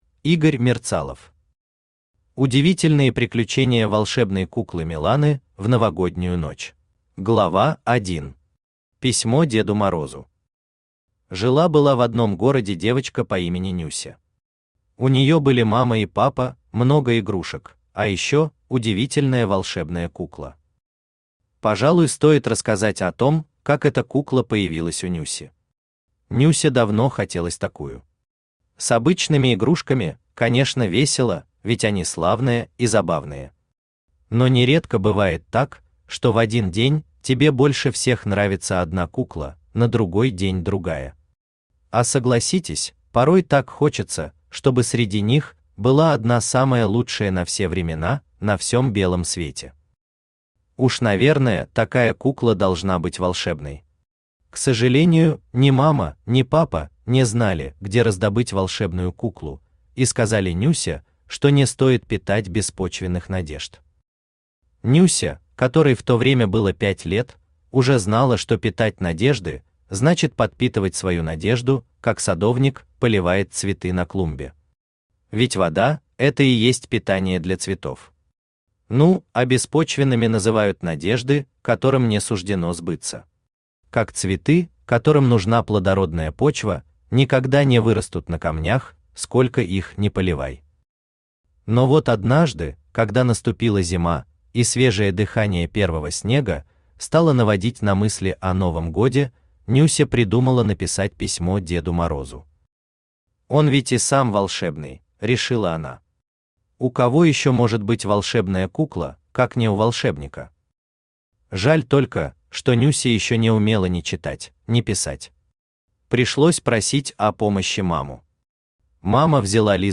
Aудиокнига Удивительные приключения волшебной куклы Миланы в новогоднюю ночь Автор Игорь Валерьевич Мерцалов Читает аудиокнигу Авточтец ЛитРес.